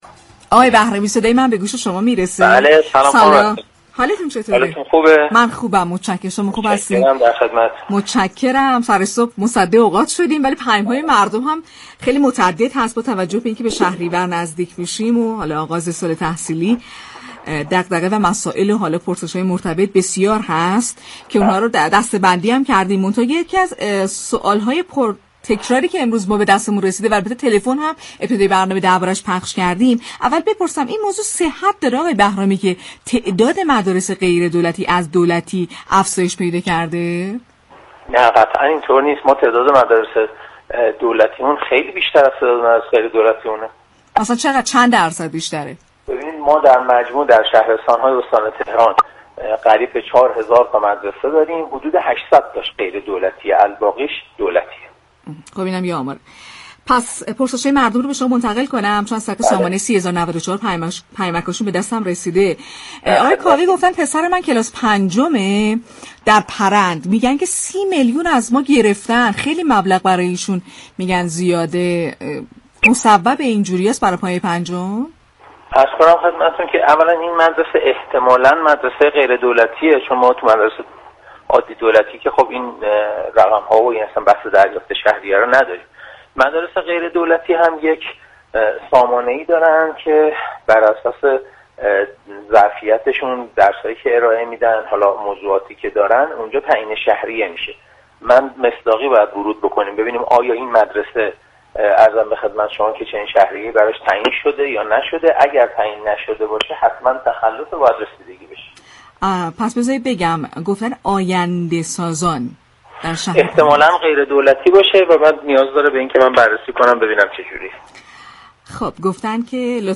به گزارش پایگاه اطلاع رسانی رادیو تهران، مسعود بهرامی مدیركل آموزش و پرورش شهرستان‌های استان تهران در گفت و گو با «شهر آفتاب» اظهار داشت: در مجموع در شهرستان‌های استان تهران حدود چهار هزار مدرسه داریم كه از این تعداد 800 مدرسه غیر دولتی و مابقی دولتی هستند.